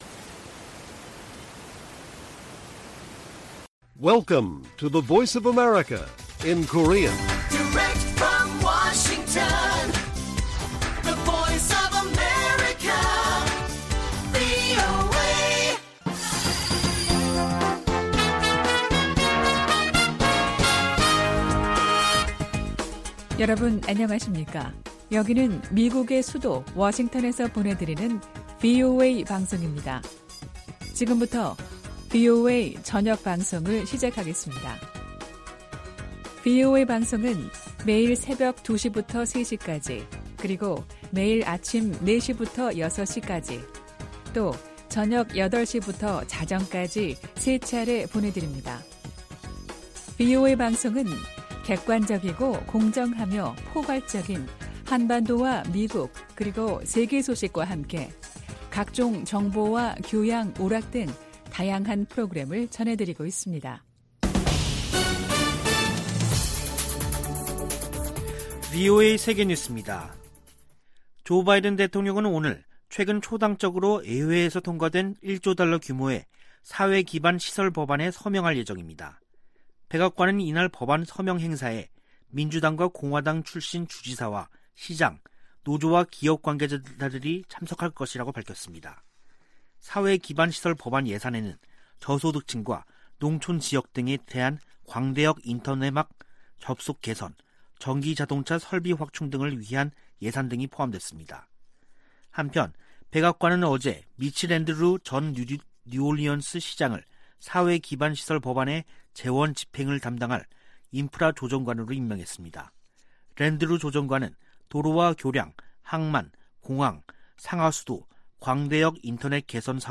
VOA 한국어 간판 뉴스 프로그램 '뉴스 투데이', 2021년 11월 15일 1부 방송입니다. 미국과 한국 사이에 종전선언 논의가 이어지는 가운데 북한이 유엔군사령부 해체를 연이어 주장하고 있습니다. 미 국무부 동아시아태평양 담당 차관보는 미국이 북한에 전제조건 없는 대화 제안을 했으며, 북한의 답을 기다리고 있다고 말했습니다.